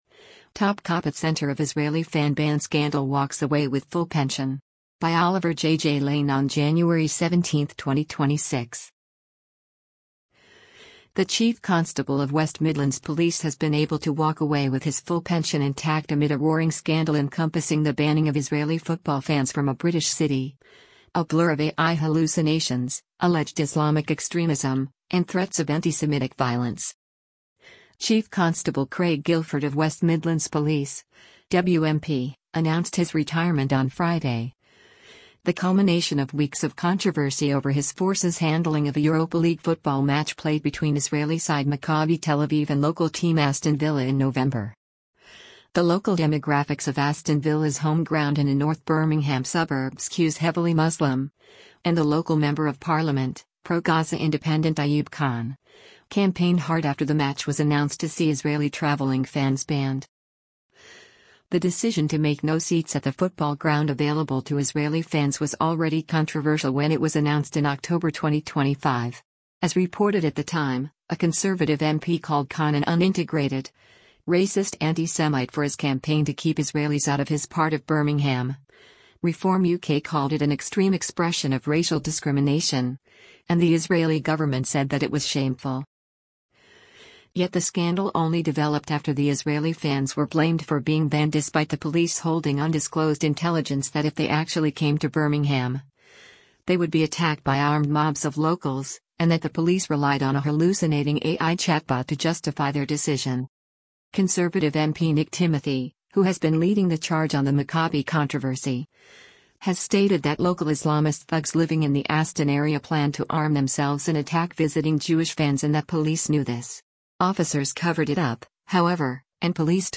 Chief Constable of West Midlands Police, Craig Guildford, takes part in a phone-in on LBC